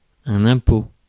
Ääntäminen
Synonyymit taxe moneage Ääntäminen France: IPA: [ɛ̃.po] Haettu sana löytyi näillä lähdekielillä: ranska Käännös Ääninäyte Substantiivit 1. tax US 2. toll 3. levy Suku: m .